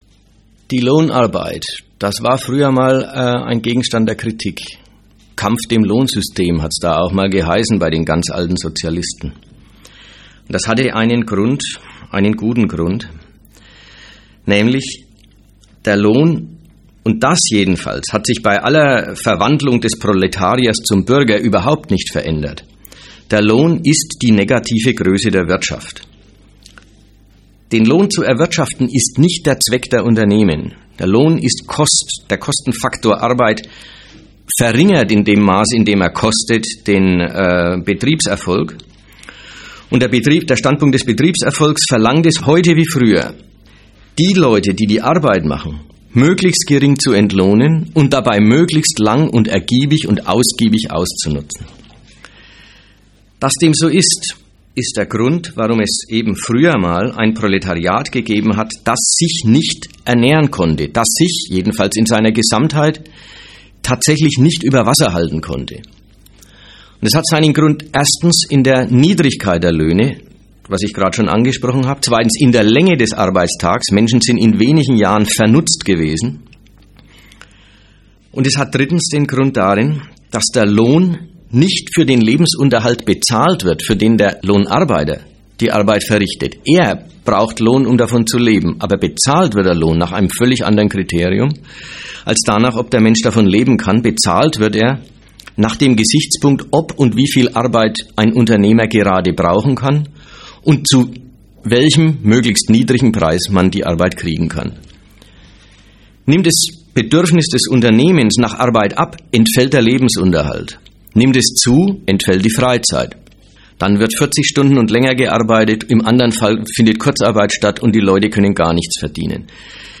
Gliederung des Interviews: Was ist Lohnarbeit?